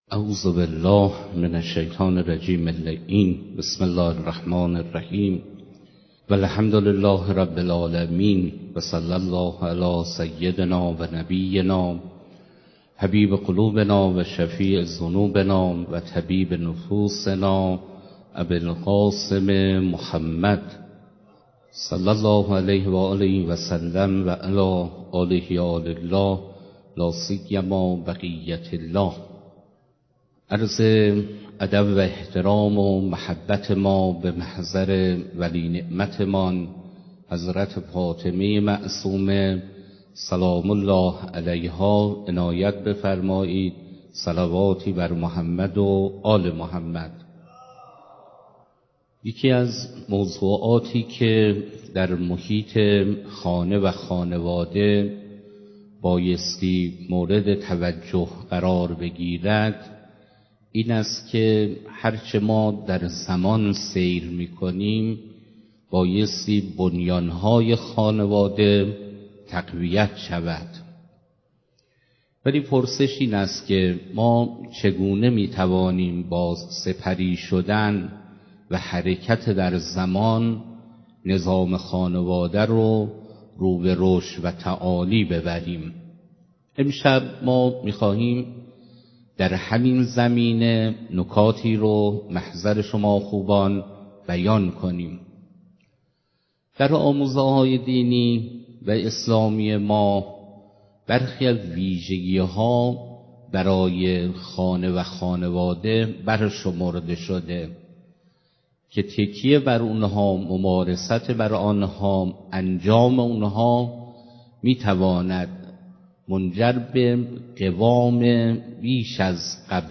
سخنرانی‌ها